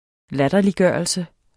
Udtale [ -ˌgɶˀʌlsə ]